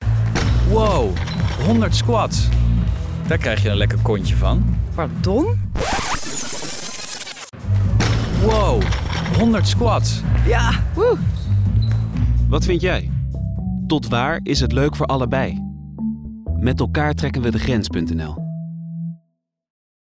Pardon?Geluid van een bandje dat wordt teruggespoeld., Woow 100 squats!